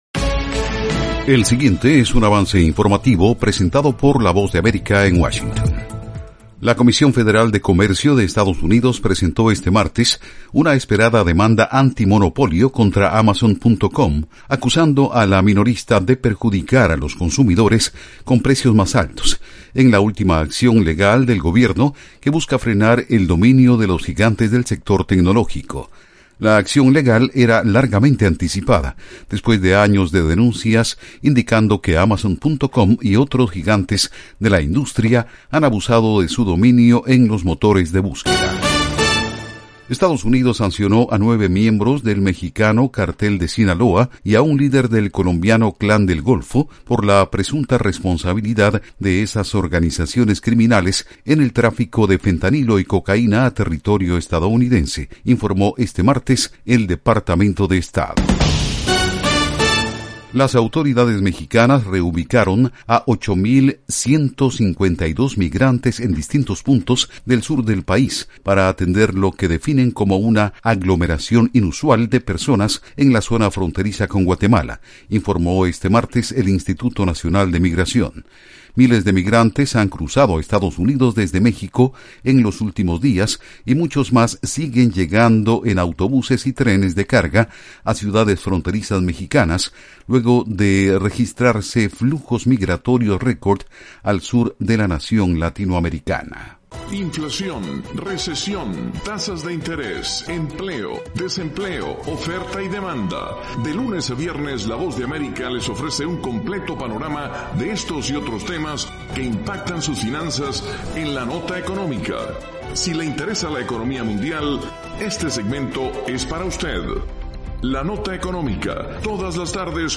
El siguiente es un avance informativo presentado por la Voz de América en Washington